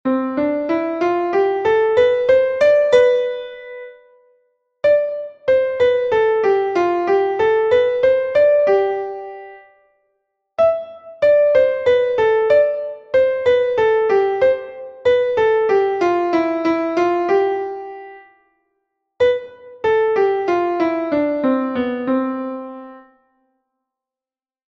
Melodic reading practice 1
Exercise 2: major scale
In this exercise you will find consecutive notes (the scale), articulation, and accentuation (staccatissimo, tenuto, slur, sforzando, fermata), as well as dynamics.